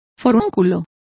Complete with pronunciation of the translation of boil.